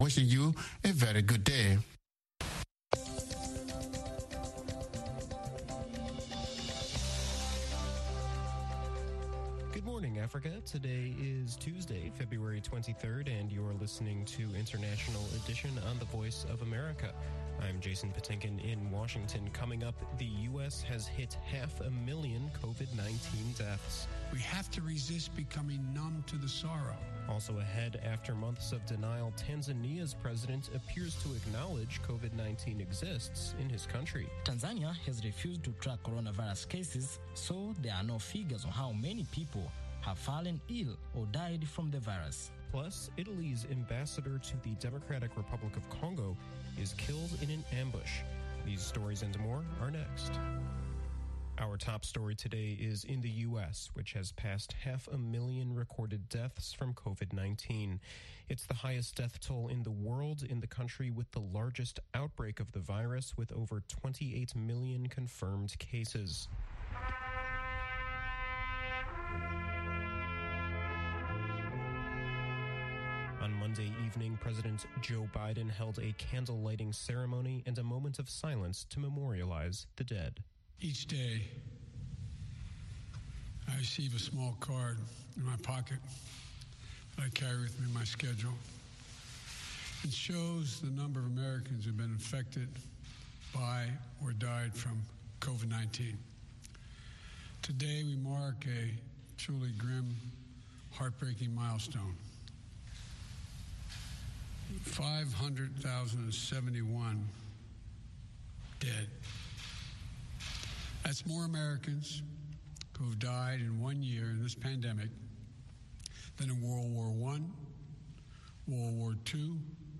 International Edition delivers insight into world news through eye-witnesses, correspondent reports and analysis from experts and news makers. We also keep you in touch with social media, science and entertainment trends.